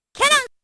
Cammy's spiral arrow move also has a different voice clip. In the sample, she just says "cannon".
cannon.mp3